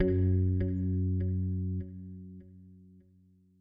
这些样本是使用各种硬件和软件合成器以及外部第三方效果创建的。